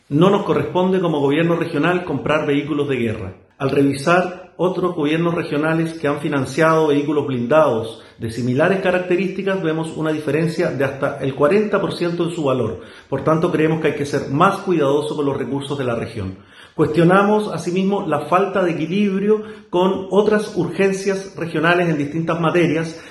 El consejero Regional, Javier Sandoval, cuestionó el valor que tienen estas camionetas, el que asciende a los 2.200 millones de pesos por cuatro unidades, esto en consideración a otras regiones que las han comprado más baratas.